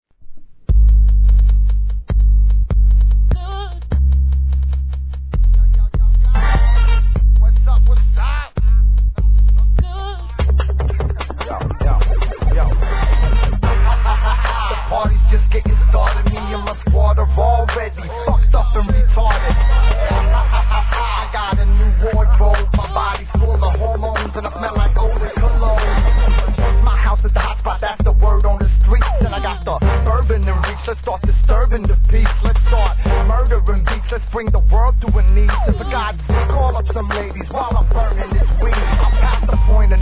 G-RAP/WEST COAST/SOUTH
サウス・サウンドのツボをきっちり捉えた手堅いスウェーデ発のBOUNCE!!